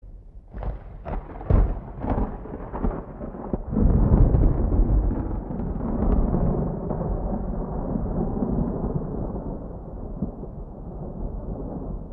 gok-gurultusu-ve-yagmur-ses-efekti-mp3cut.mp3